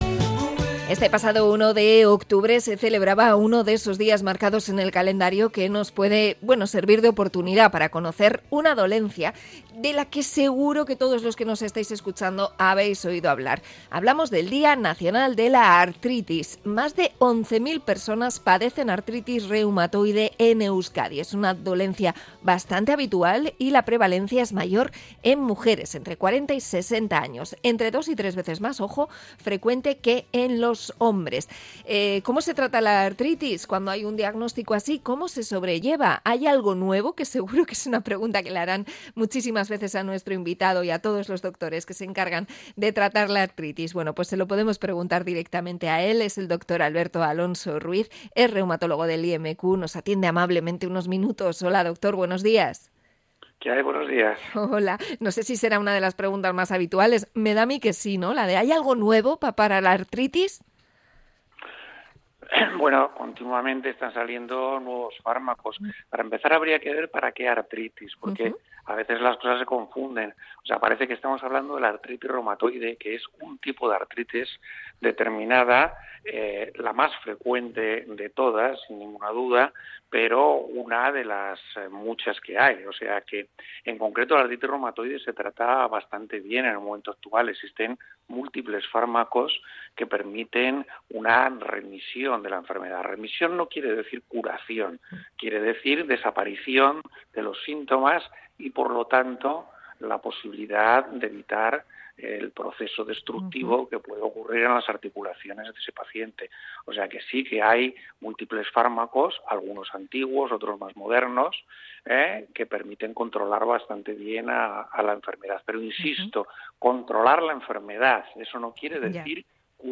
Entrevista a reumatólogo por el día de la artritis